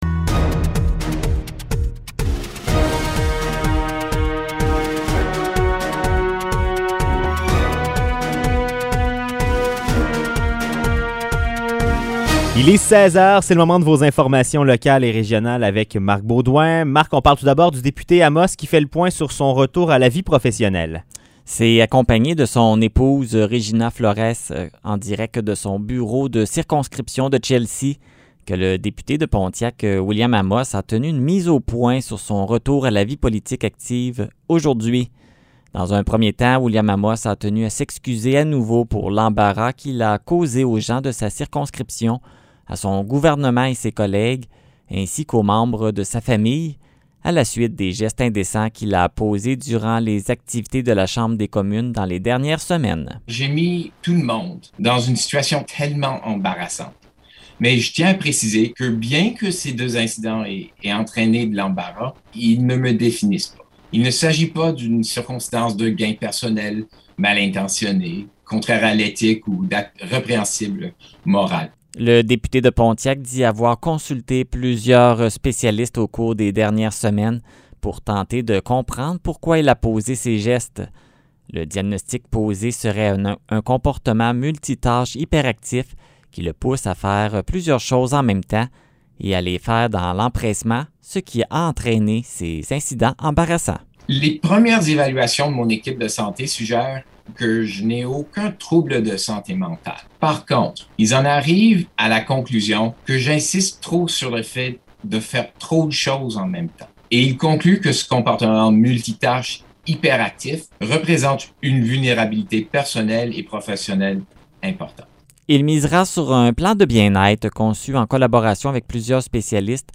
Nouvelles locales - 7 juillet 2021 - 16 h